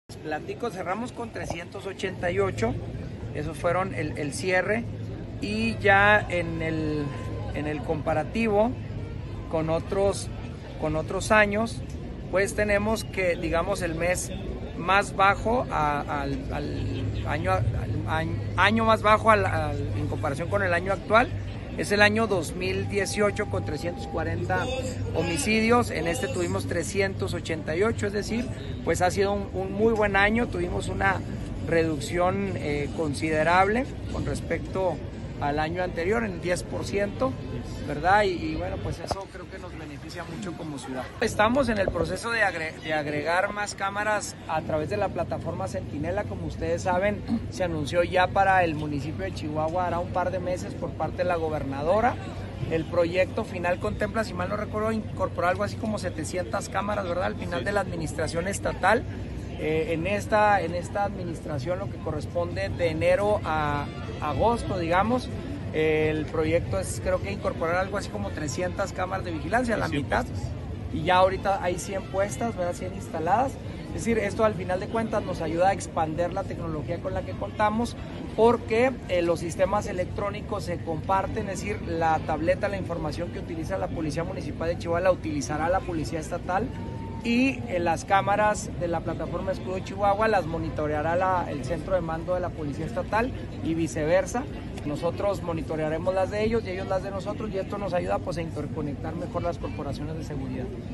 AUDIO: MARCO ANTONIO BONILLA, PRESIDENTE MUNICIPAL DE CHIHUAHUA